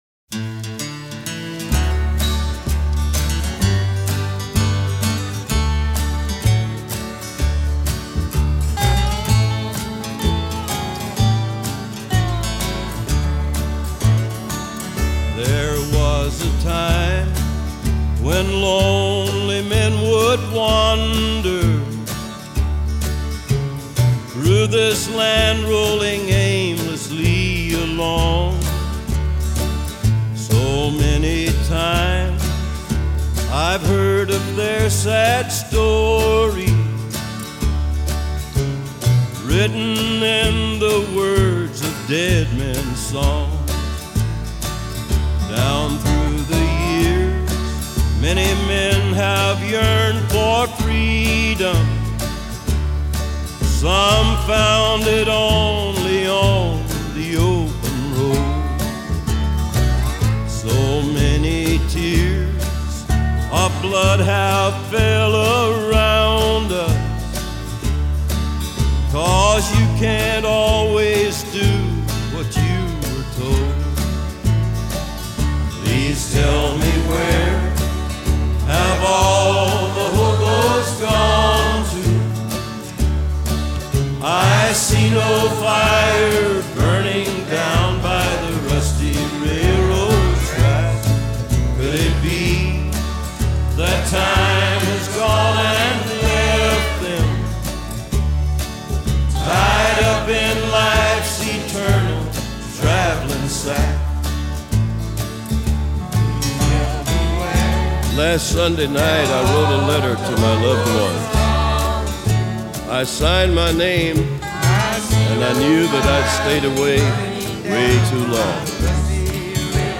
Жанр: Country, Rock